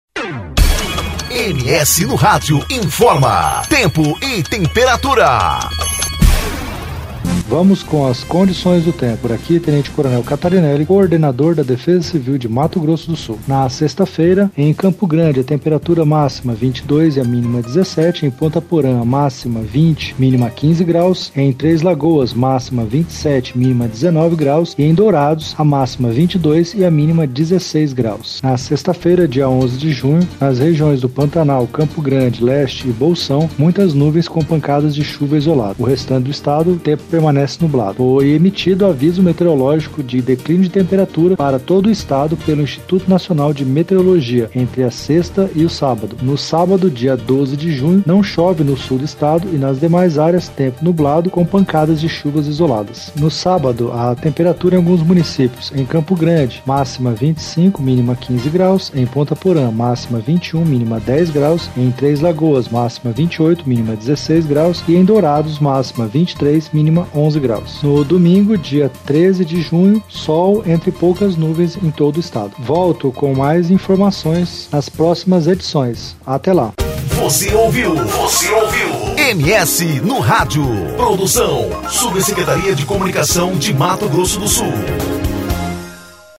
Previsão do Tempo com o coordenador estadual de Defesa Civil Tenente Coronel Fábio Catarineli, para o fim de semana.